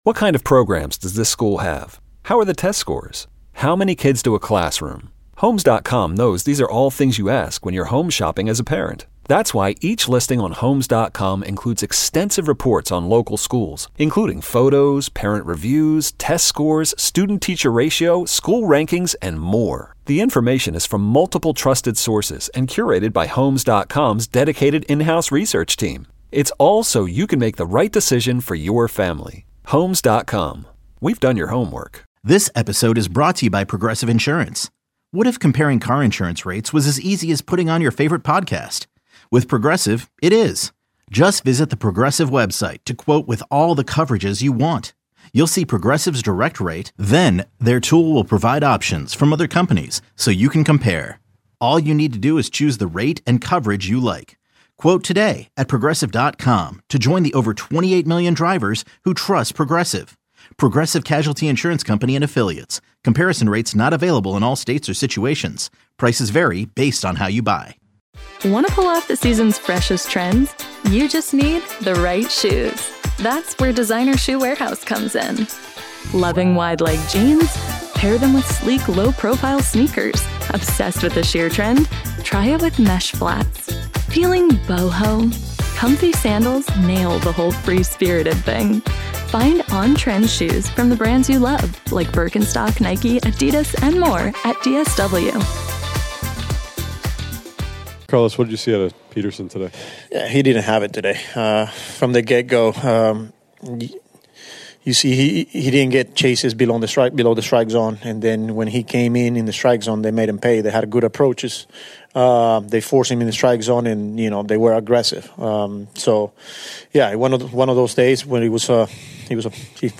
It was a tough start for the Mets and despite eventually tying the game they still fell to the Marlins 11-8 and after the game Carlos Mendoza met with the media to discuss the struggles from David Peterson who allowed a career high 8 earned runs in his short start.